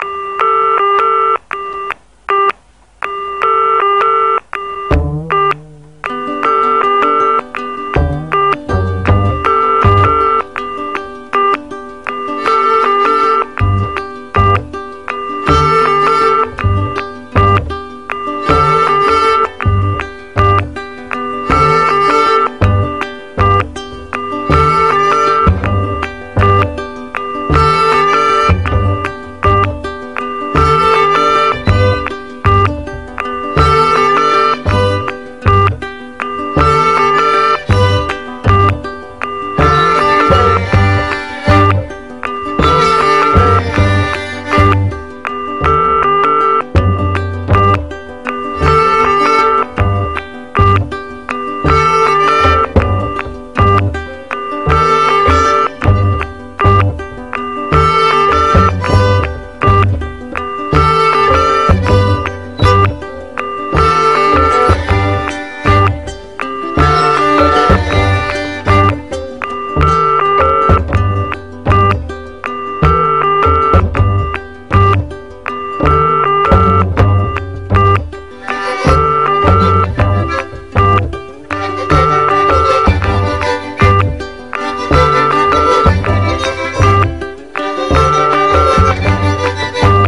SOUL
ダイナミック＆ハスキー・ヴォーカルのレディ・ソウル代表！
パワフルなファンキー・ソウル
漆黒のミディアム・グルーヴ